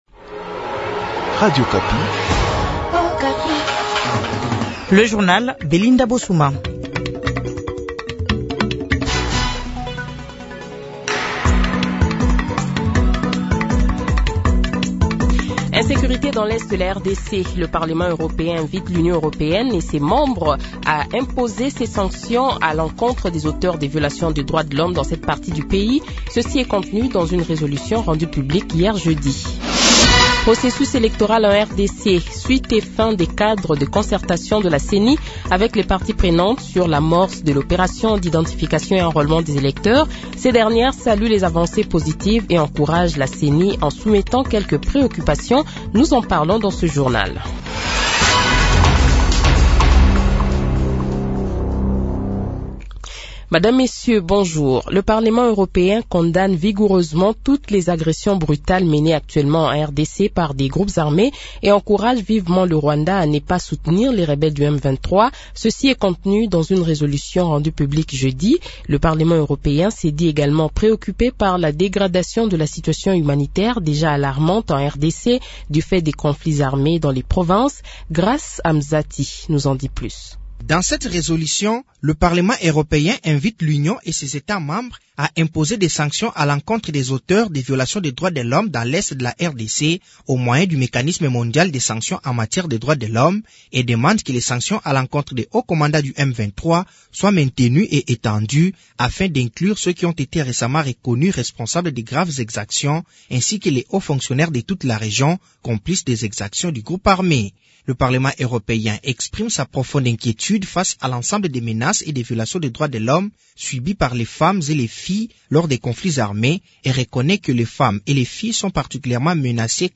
Le Journal de 7h, 25 Novembre 2022 :